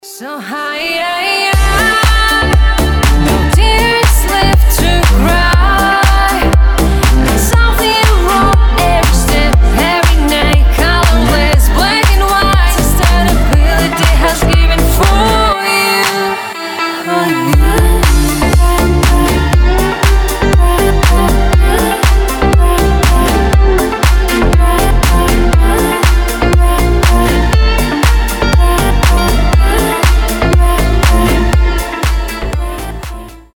deep house
женский голос
Классная танцевальная музыка на звонок